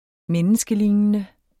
Udtale [ ˈmεnəsgəˌliˀnənə ]